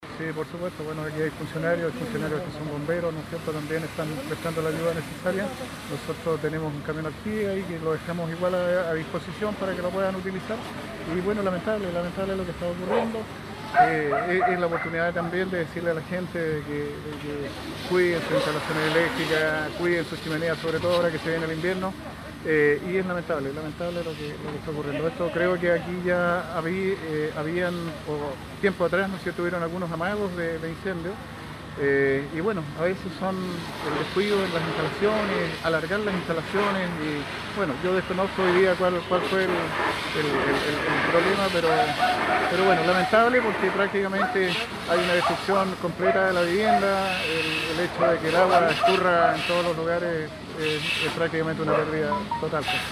El alcalde de Quemchi Gustavo Lobos indicó que pese a la ayuda y esfuerzo de los voluntarios, la afectación al inmueble ha sido total, ya sea por efecto del fuego o del agua.